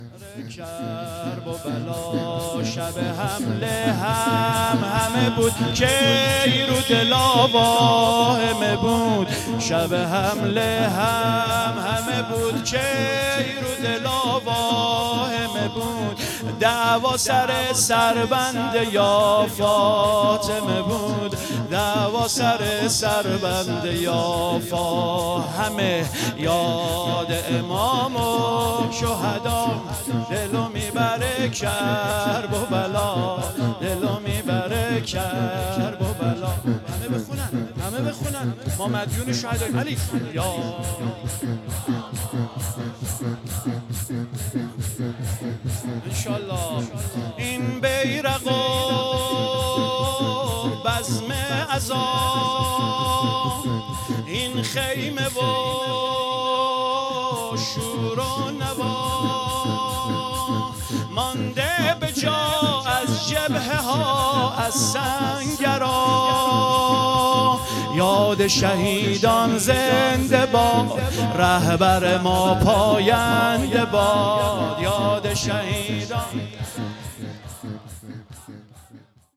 شور زیبا - یاد شهیدان